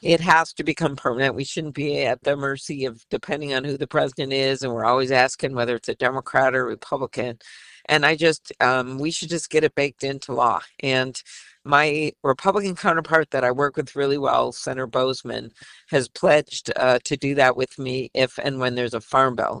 Klobuchar says Bushmills officials are happy President Trump signed an executive order allowing 15% ethanol-blend gasoline to be sold nationwide over the coming summer but says it should become part of permanent law: